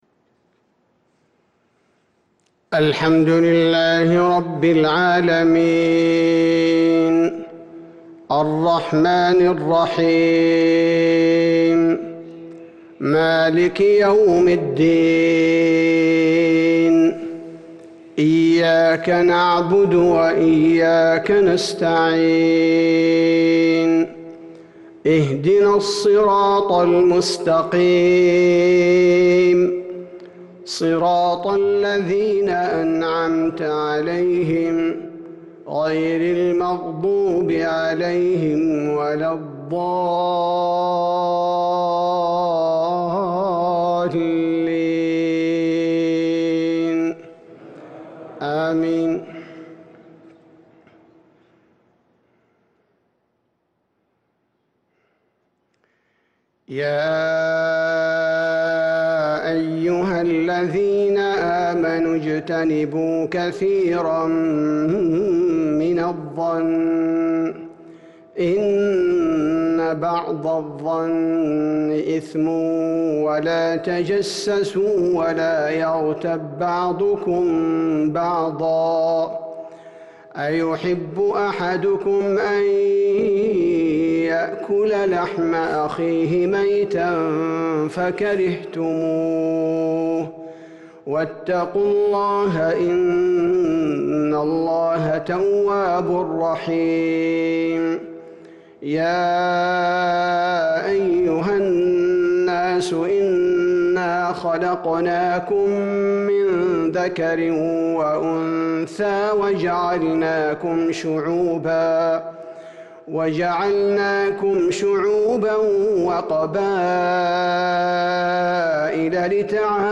عشاء ٣-٧- ١٤٤٣هـ سورتي الحجرات والنازعات | Isha prayer from Surah al-Hujurat & an-Nazi`at 4-2-2022 > 1443 🕌 > الفروض - تلاوات الحرمين